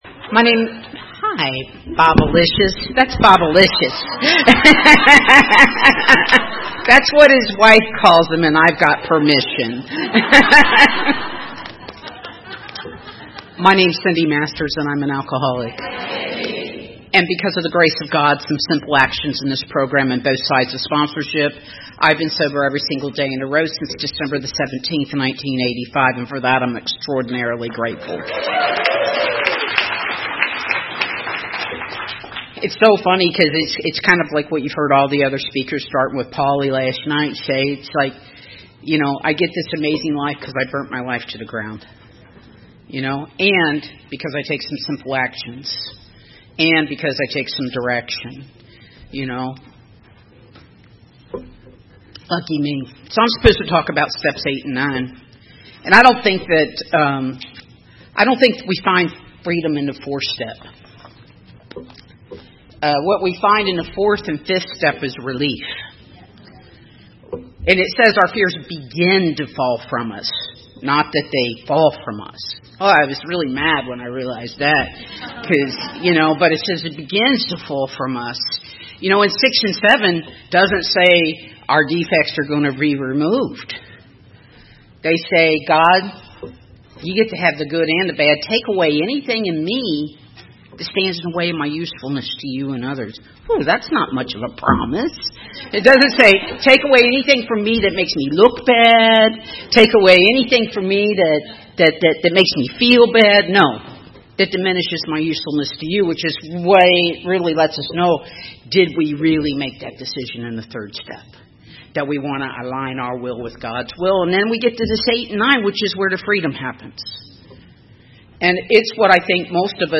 TYPE: AA, Female, Workshop